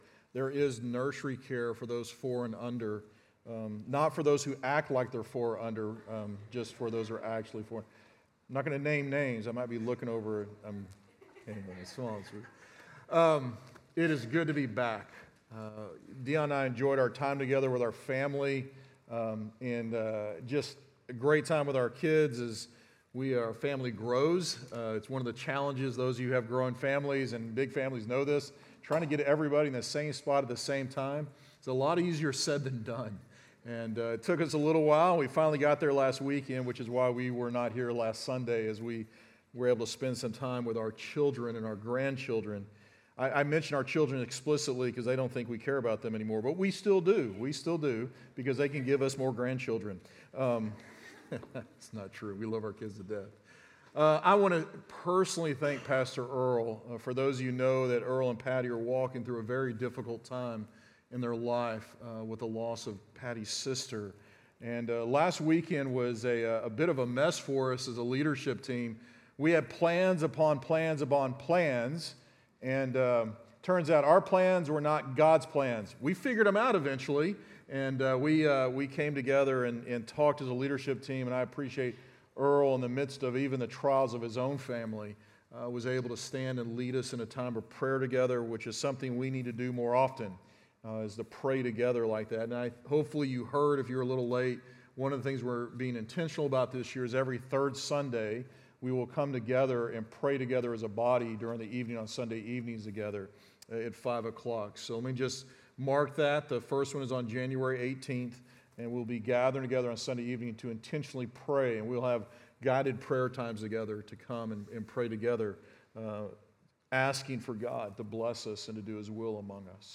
Love and Hope 1 Thessalonians 5:14-18 Sermon Audio Only Sermon Video